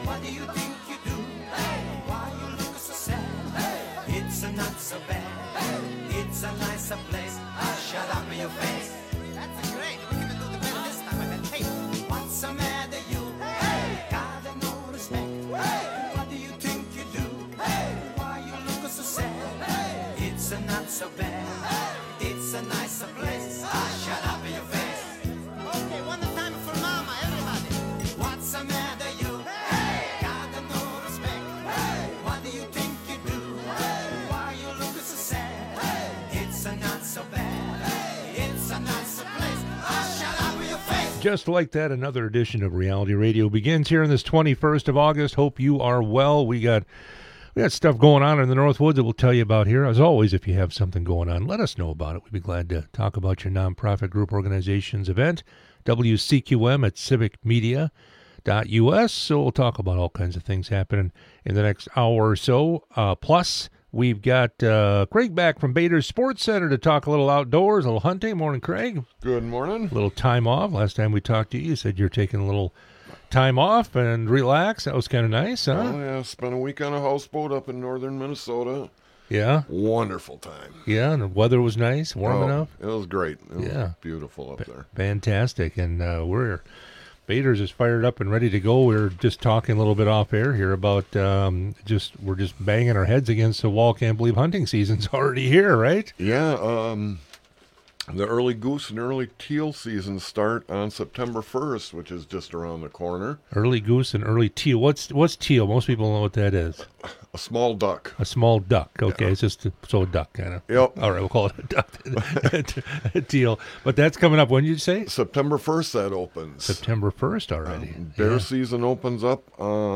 Interviews and special broadcasts from 98Q Country in Park Falls.